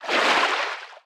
Sfx_creature_babypenguin_swim_glide_05.ogg